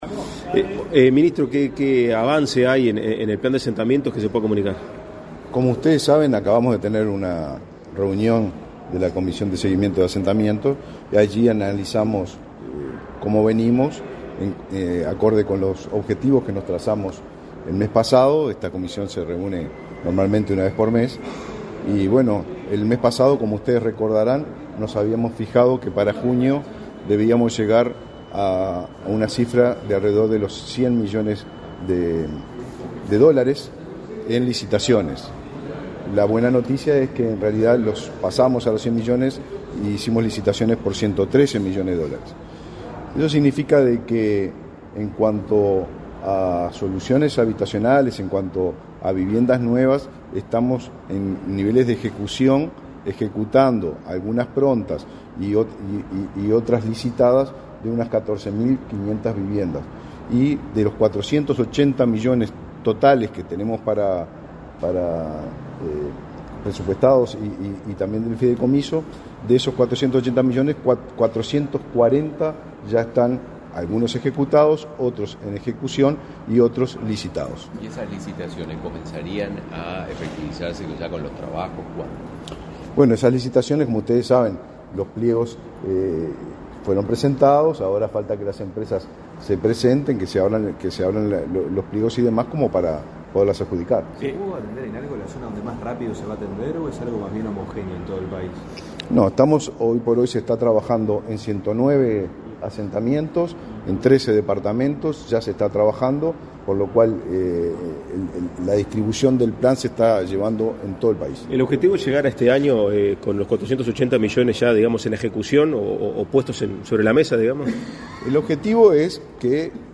Declaraciones a la prensa del ministro de Vivienda, Raúl Lozano, y el titular del Congreso de Intendentes, Fernando Echeverría
Este 20 de julio, el titular del Ministerio de Vivienda y Ordenamiento Territorial, Raúl Lozano, y el del Congreso de Intendentes, Fernando Echeverría, informaron a la prensa acerca de los avances.